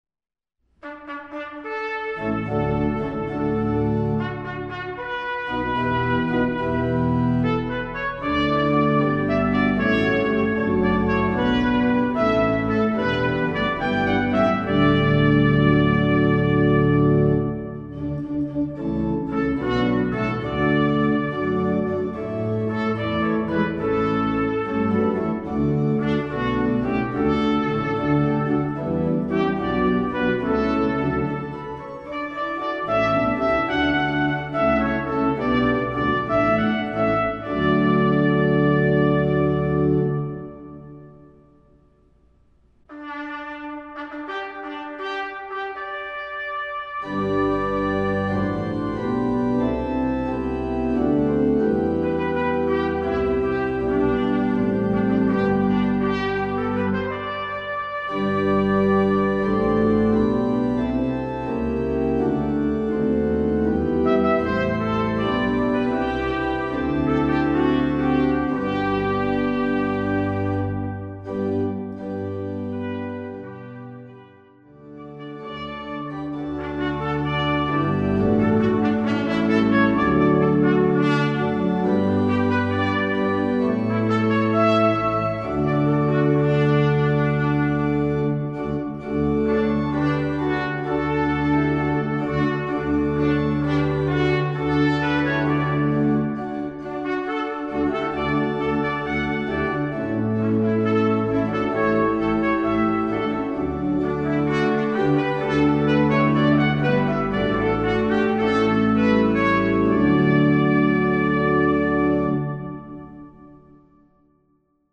Voicing: TR W/org